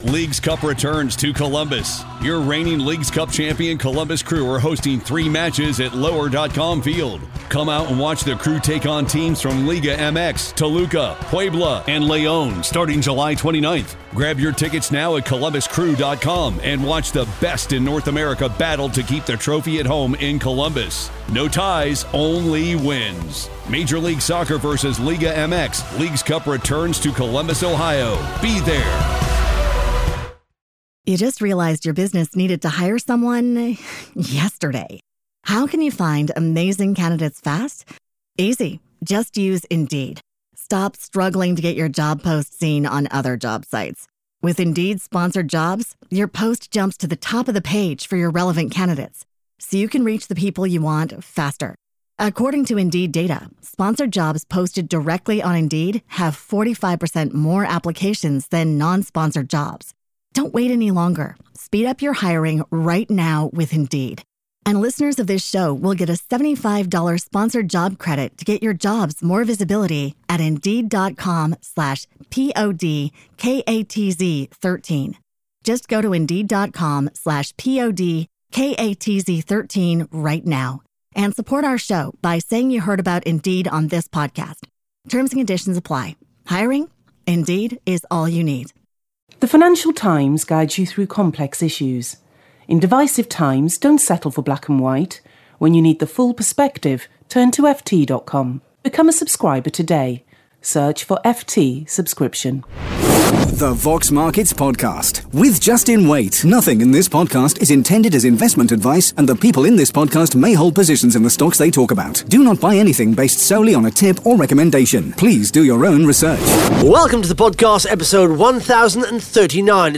(Interview starts at 57 seconds)